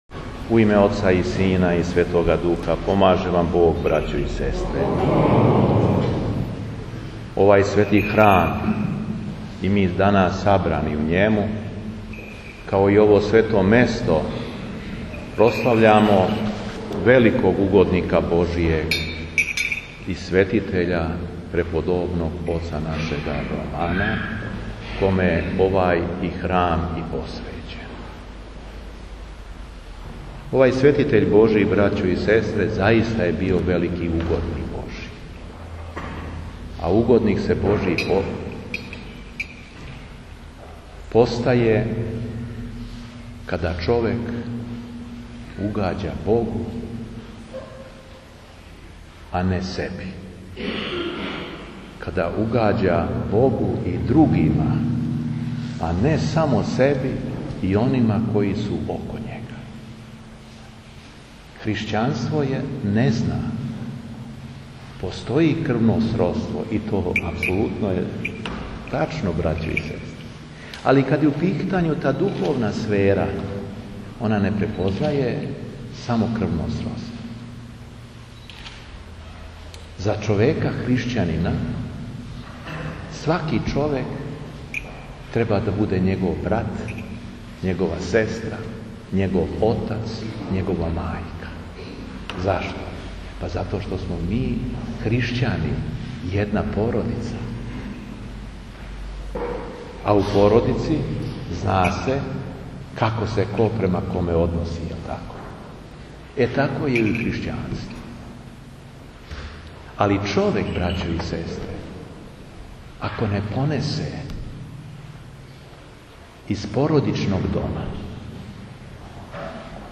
Беседа епископа шумадијског Г. Јована
ЛИТУРГИЈА У РЕКОВЦУ – 29. августа 2015. године